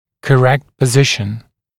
[kə’rekt pə’zɪʃn][кэ’рэкт пэ’зишн]правильное положение